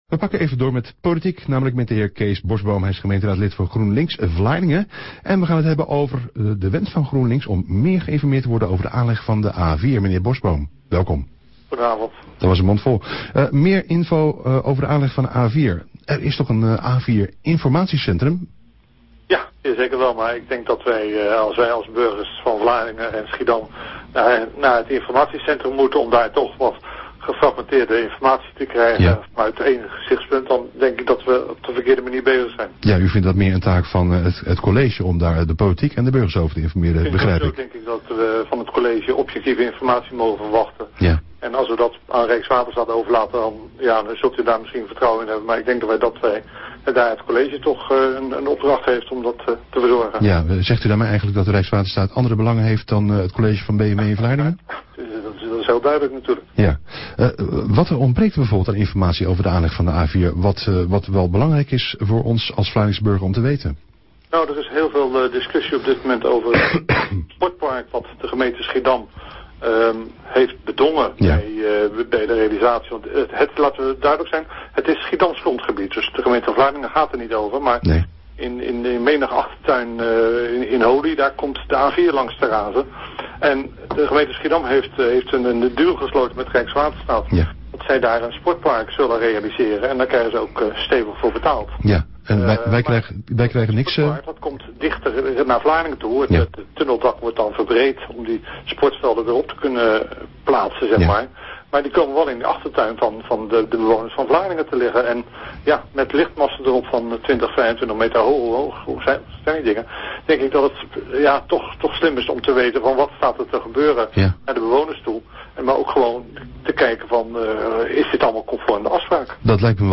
HIER naar het interview van Omroep Vlaardingen met Kees Borsboom in het RadioMagazine op donderdag 7 maart 2013